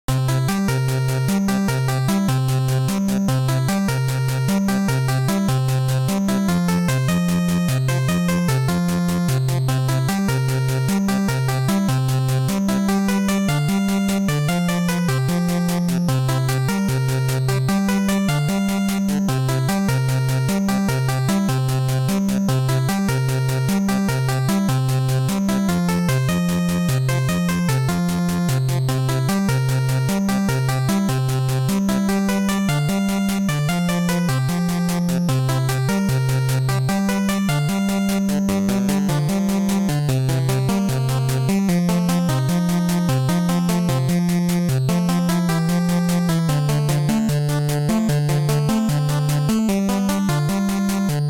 8-Bit Looping "12 Bar Blues" Music
This was done as a bit of practice making accurate 8-bit music with 0CC-Famitracker, a backwards-compatible fork of FamiTracker with a few extra features added in. This was playing around with something originally based in twelve-bar blues chord progressions while keeping to NES sound limitations without add-on chips. The result is not very blues at all, but it functions as a decently seamless music loop.